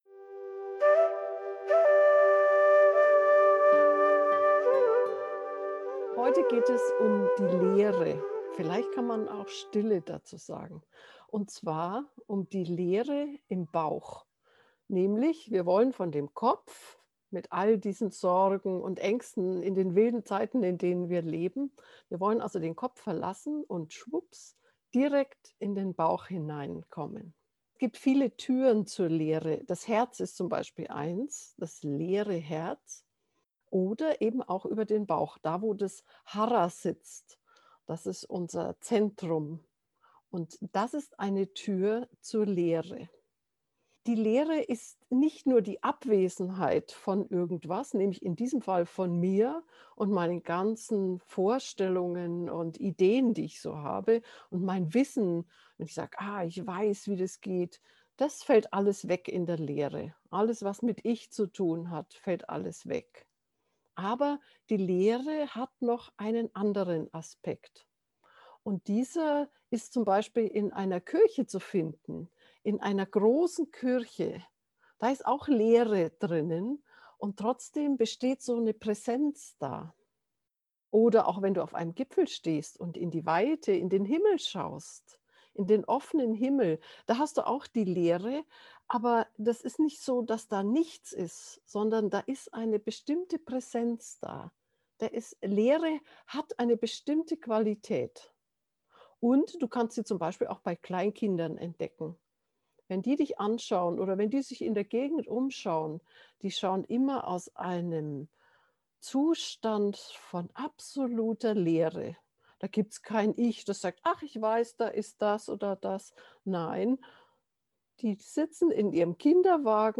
Geführte Meditationen Folge 97: Aus dem Kopf in die Leere im Bauch Play Episode Pause Episode Mute/Unmute Episode Rewind 10 Seconds 1x Fast Forward 10 seconds 00:00 / 13:03 Subscribe Share RSS Feed Share Link Embed
leere-im-bauch-gefuehrte-harameditation.mp3